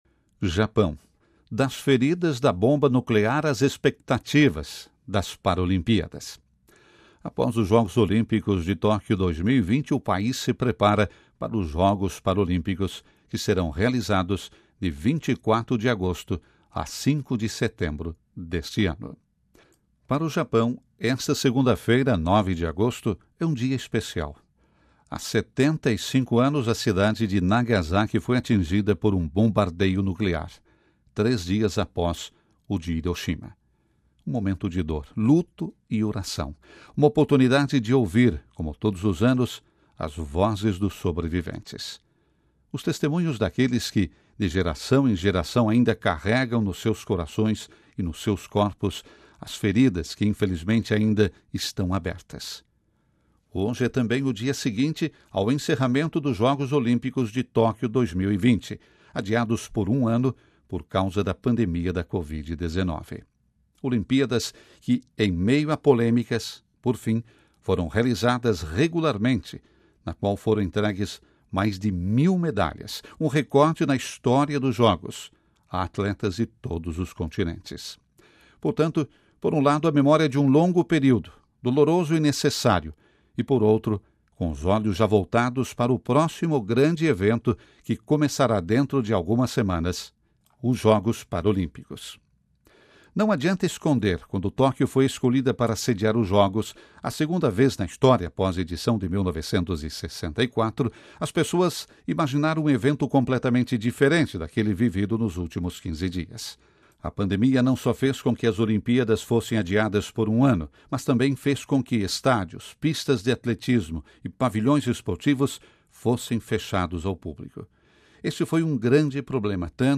Os Paraolímpicos e o foco nos mais vulneráveis